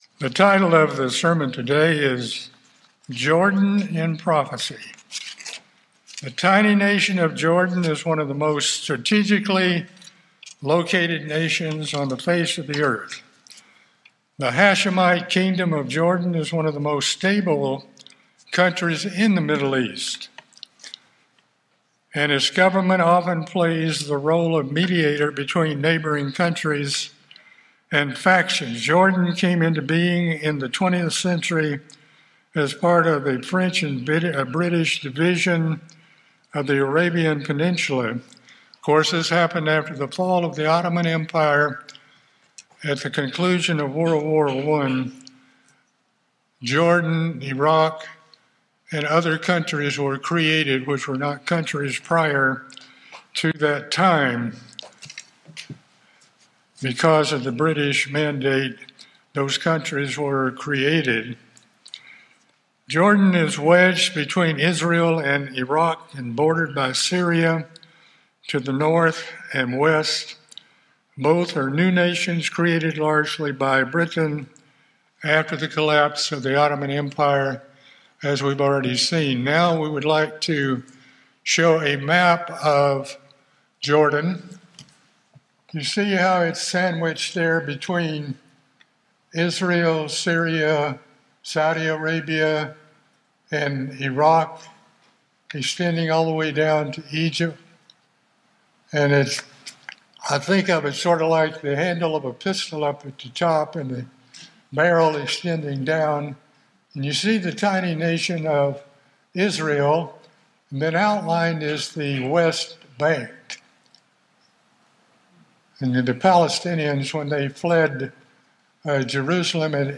This sermon shows the origin of the Hashemite kingdom of Jordan from the Bible dating back to the days of Abrahan and Lot. It also explores how Lot became the father of Moab and Ammon and that Moab and Ammon are modern day Jordan in Bible prophecy.